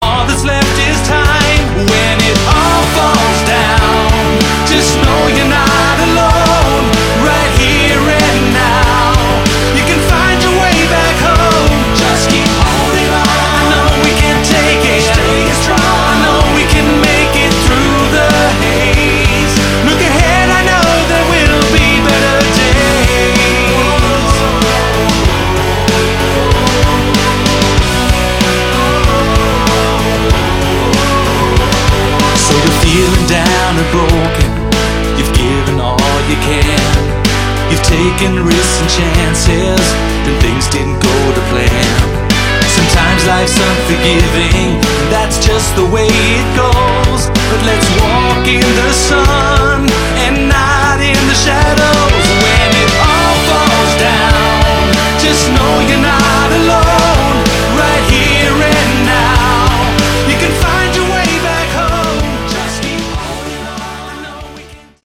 Category: Melodic Rock
All vocals & Instruments